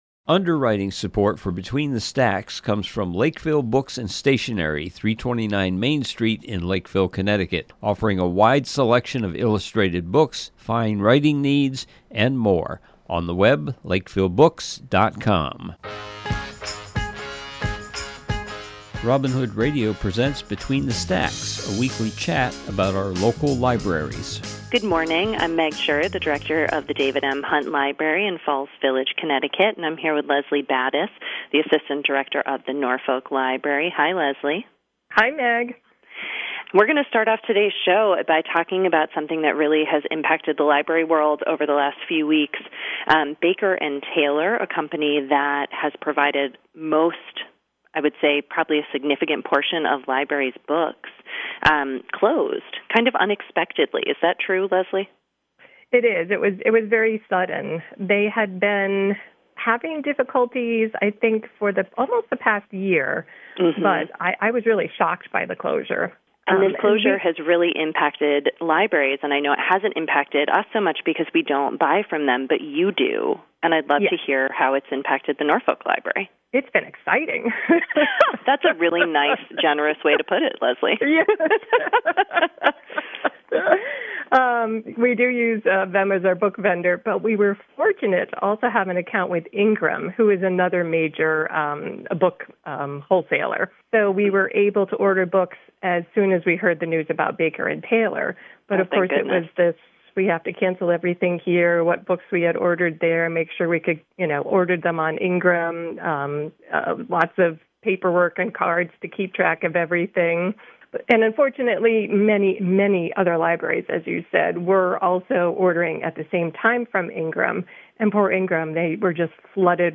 This week’s program is a conversation